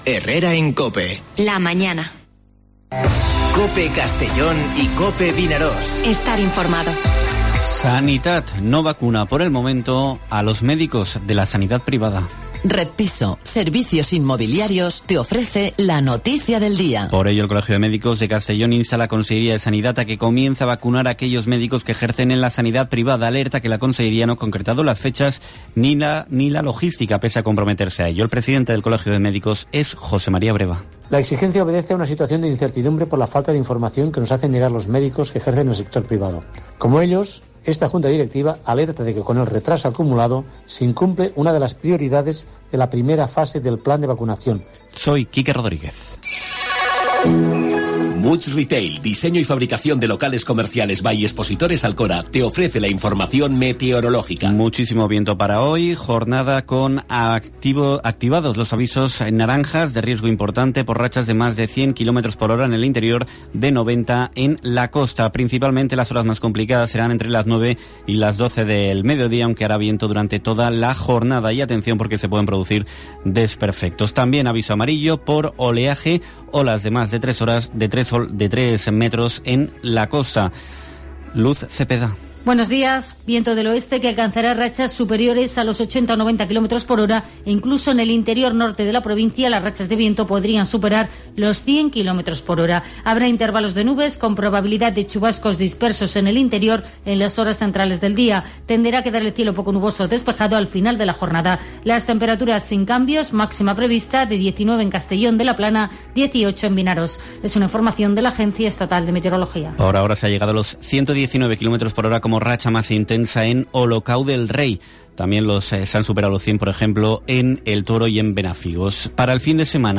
Informativo Herrera en COPE en la provincia de Castellón (22/01/2021)